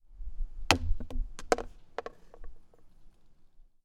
frisbee hitting lampost
Category ⚡ Sound Effects
aip09 bump bumping ding dinging disc frisbee hit sound effect free sound royalty free Sound Effects